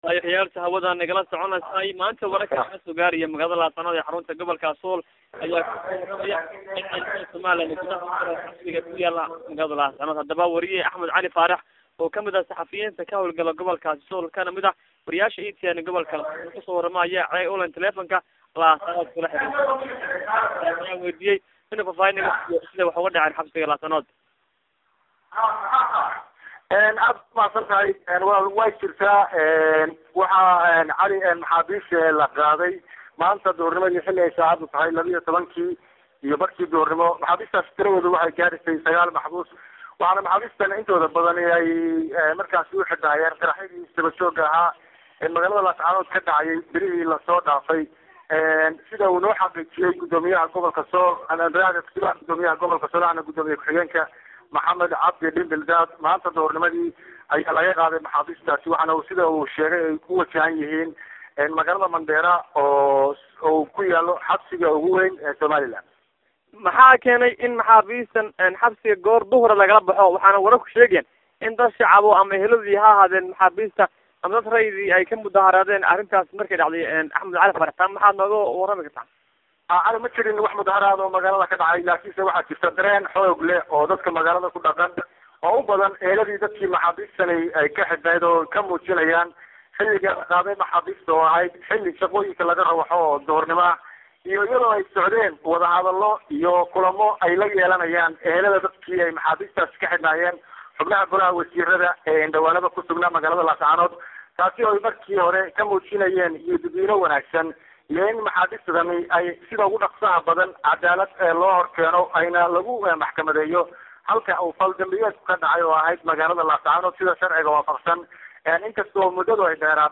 Waraysiga